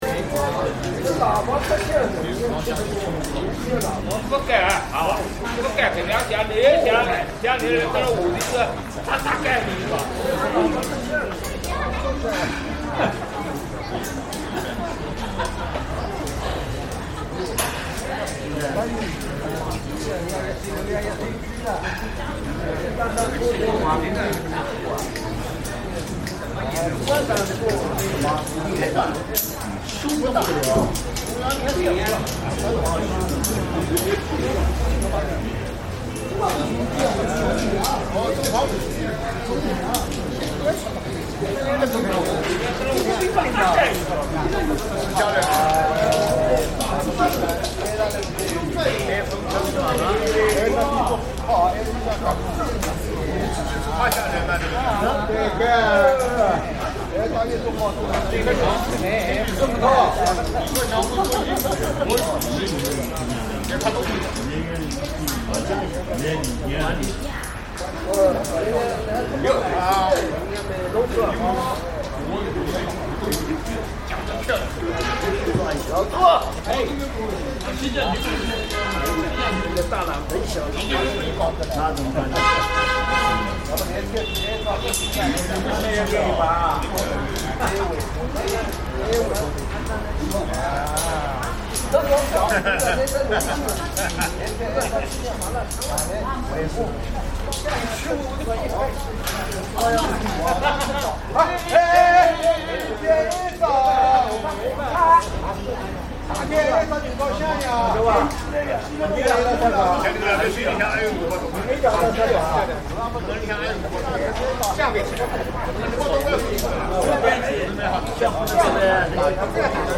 Guanqian Park, a refined roadside garden, hosts elderly chess players in tight-knit groups, clearly familiar.
Some laugh, others argue heatedly, slapping chess pieces in excitement, creating a lively buzz. Onlookers watch quietly with poised restraint, embodying Suzhou’s cultured folk spirit. Unexpectedly found in a roadside park.